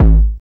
808 BASS BIN.wav